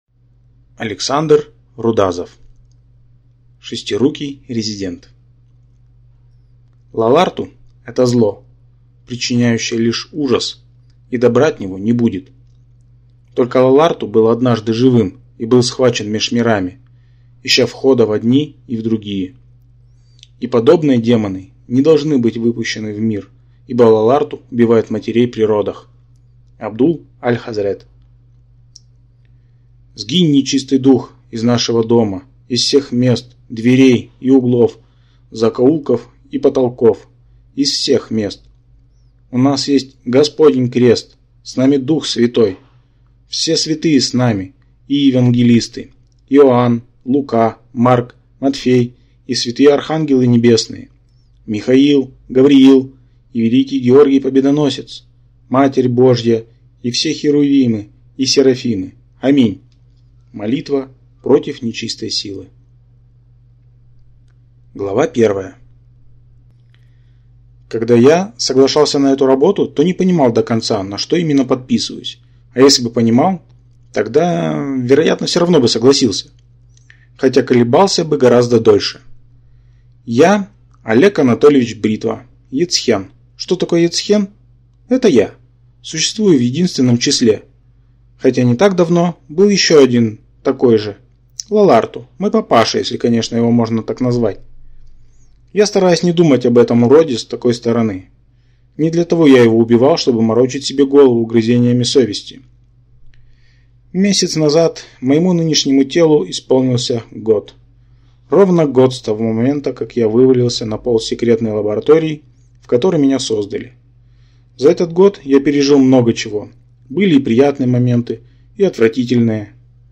Аудиокнига Шестирукий резидент | Библиотека аудиокниг
Прослушать и бесплатно скачать фрагмент аудиокниги